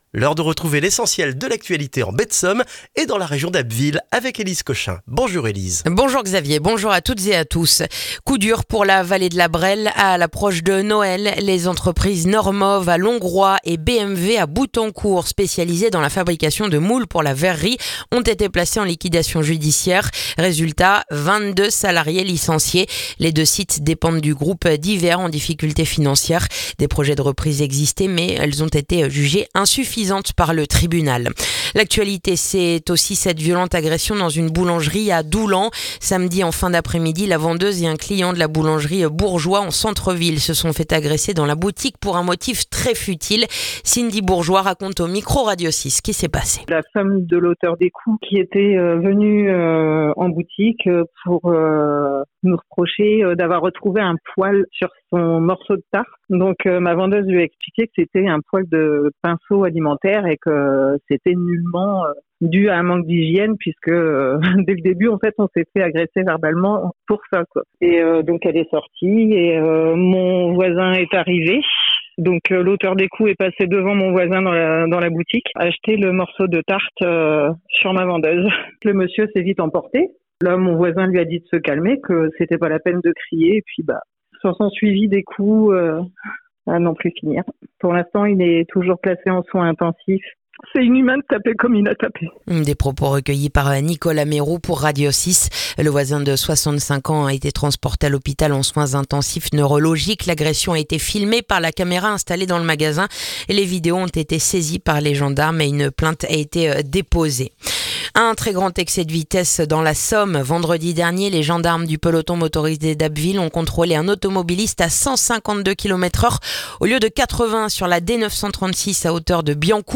Le journal du mercredi 17 décembre en Baie de Somme et dans la région d'Abbeville